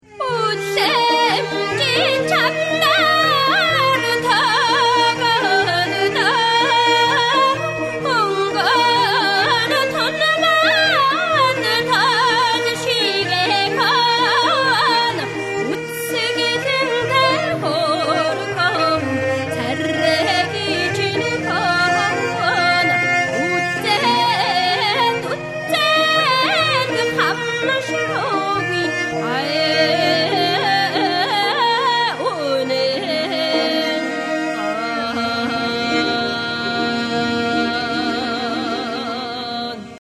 female voice
morin khuur
khuuchir
yatga